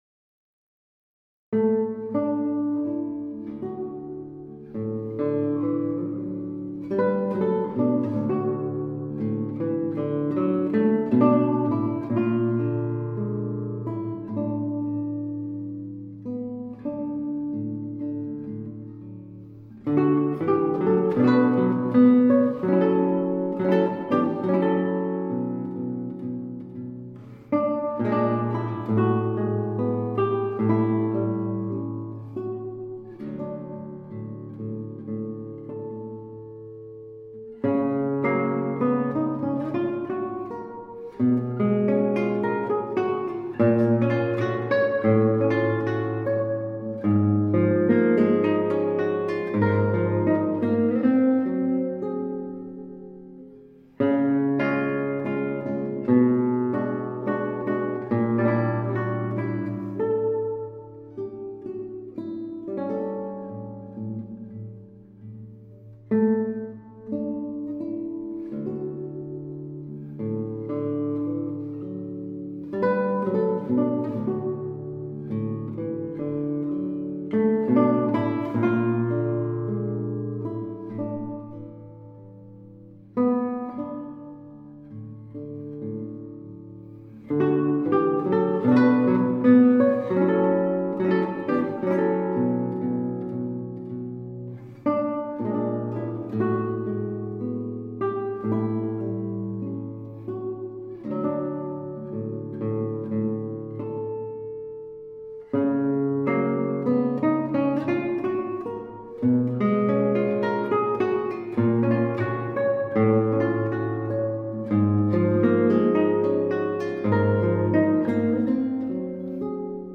クラシックギター